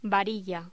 Locución: Varilla
voz